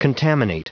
Prononciation du mot contaminate en anglais (fichier audio)
Prononciation du mot : contaminate